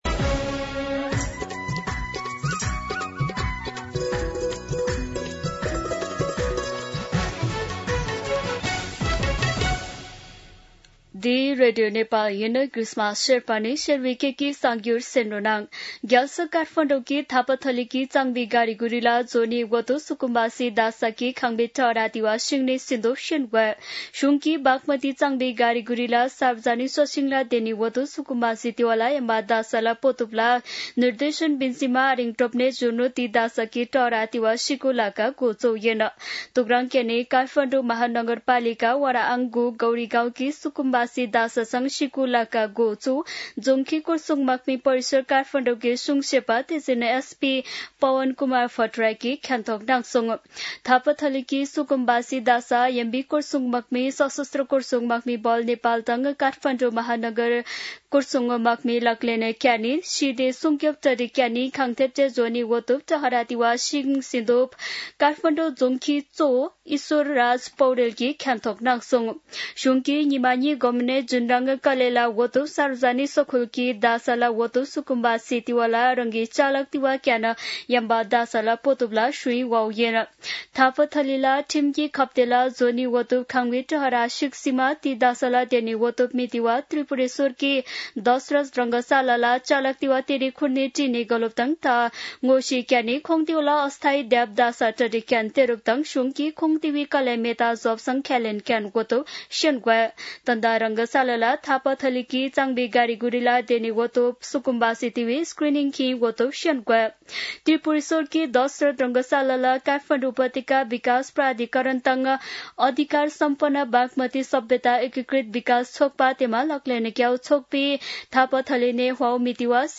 शेर्पा भाषाको समाचार : १२ वैशाख , २०८३
Sherpa-News-12.mp3